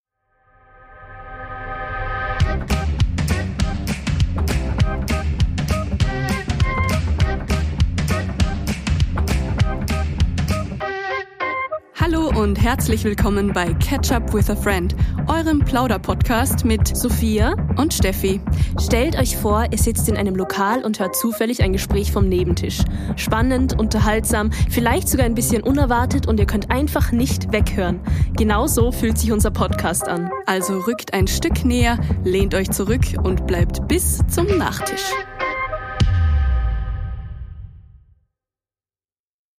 Ungefilterte Gespräche über alles, was grad Thema ist – direkt,
laut, manchmal messy, aber immer real.